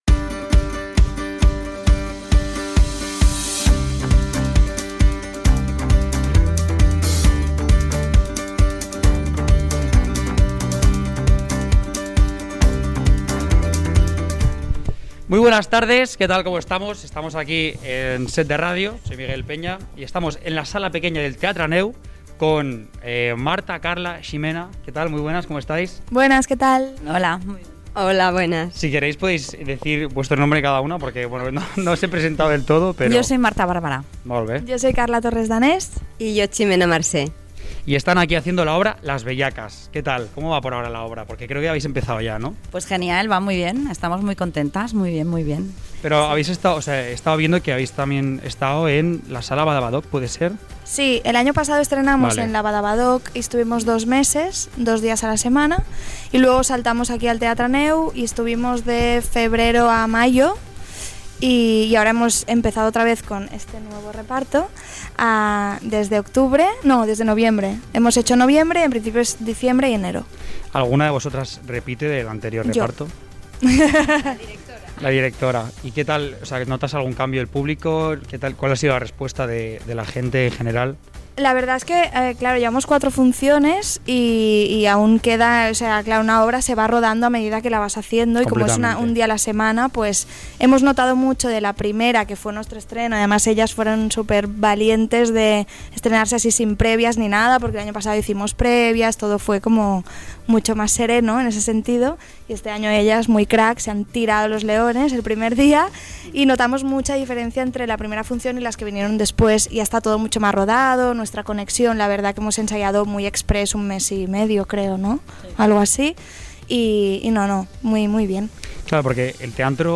bcc7a2efdef1c8fc07fa613c055c8e493230f30b.mp3 Títol 7deRàdio Barcelona Emissora 7deRàdio Barcelona Titularitat Tercer sector Tercer sector Cultural Descripció Entrevista a les integrants del grup teatral Las Bellacas.